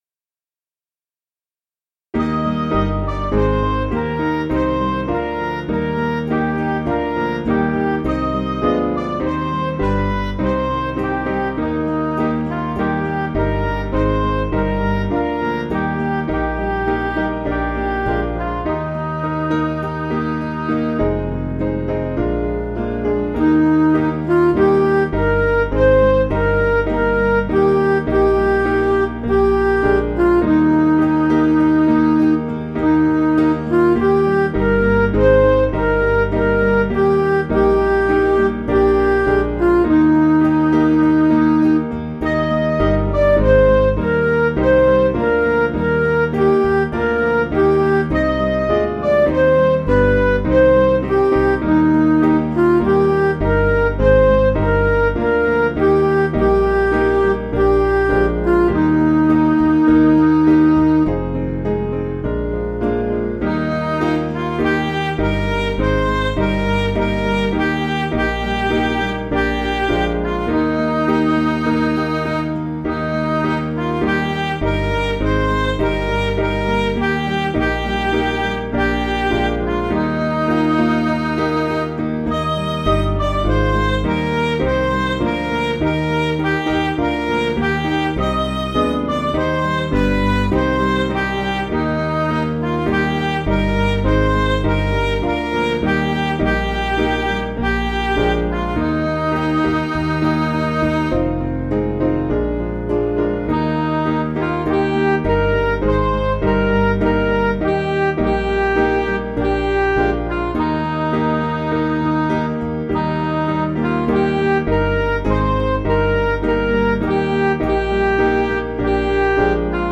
Piano & Instrumental
(CM)   4/Eb
Midi